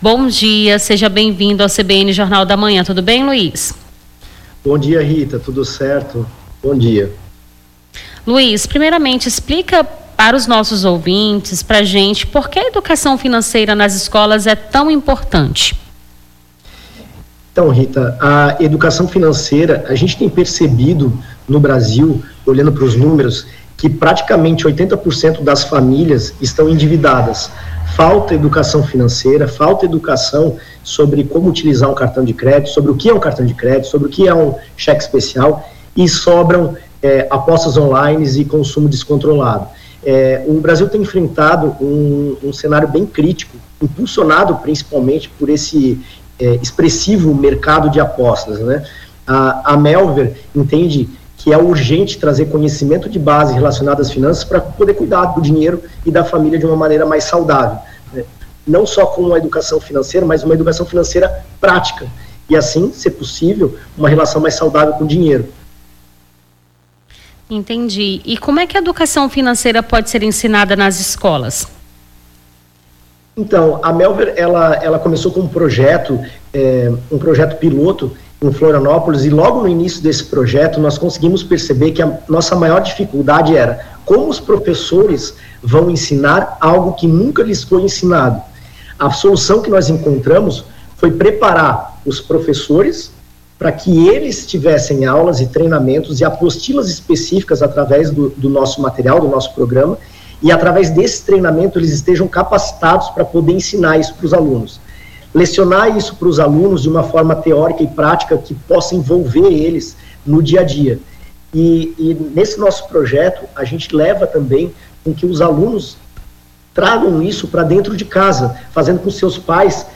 Nome do Artista - CENSURA - ENTREVISTA (EDUCACAO FINANCEIRA) 22-04-25.mp3